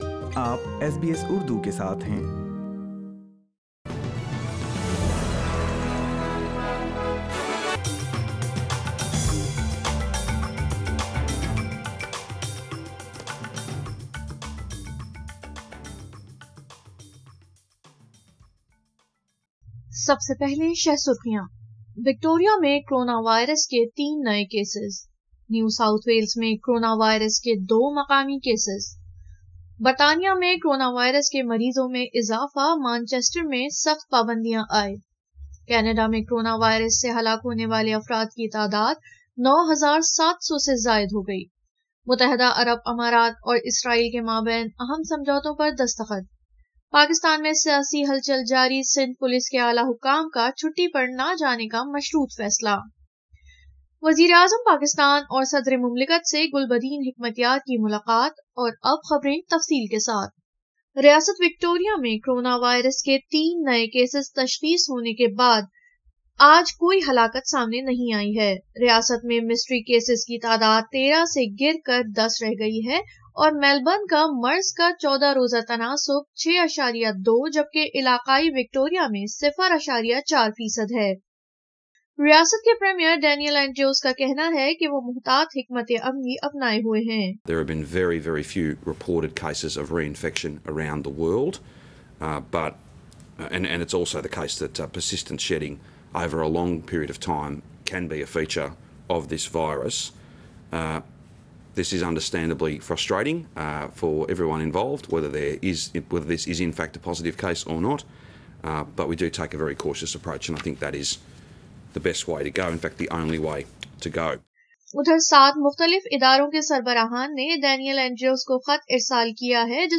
اردو خبریں 21 اکتوبر 2020